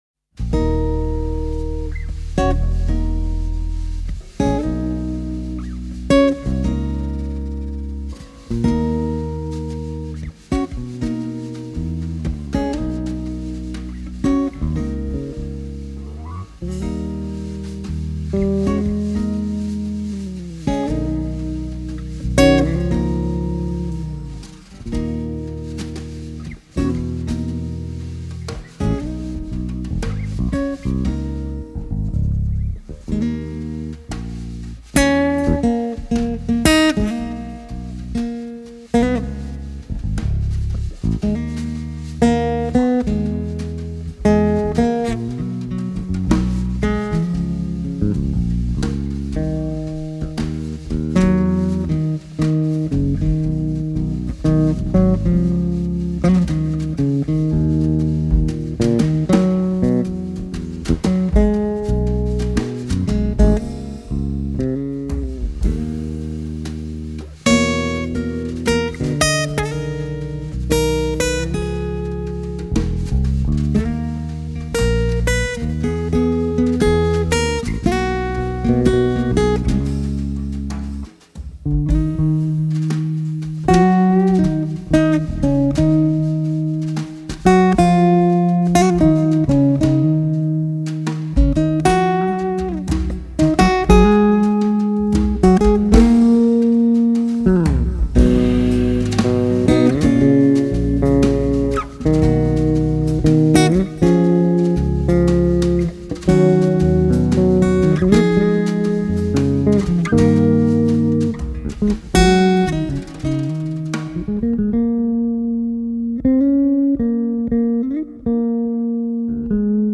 guitar
bass
drums Écouter un extrait